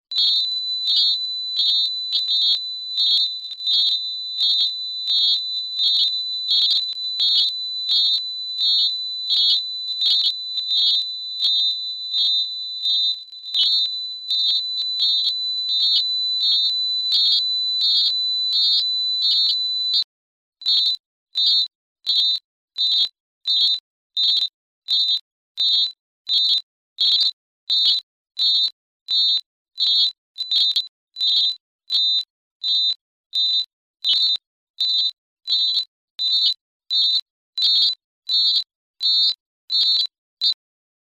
Please excuse the partially poor quality of the sound files.
The spherical satellite had a diameter of 58cm and carried 2 radio beacons transmitting on 20.005 MHz and 40.010 MHz.
This Audio file is based on a recording which was made by a group of young engineering students at the Fernmeldetechnik Department of TH Darmstadt.
The paper tape was scanned and digitized and converted into audio using Photoshop for scanning and Audacity for sound processing.